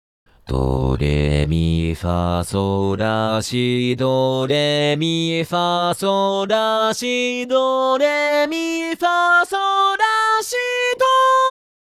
各音源の簡単な解説とサンプル音声です。
【説明】 ： 非常にスタンダードなパフォーマンス
あらゆる歌唱をそつなくこなす音源です